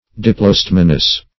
Search Result for " diplostemonous" : The Collaborative International Dictionary of English v.0.48: Diplostemonous \Dip`lo*stem"o*nous\, a. [Gr.
diplostemonous.mp3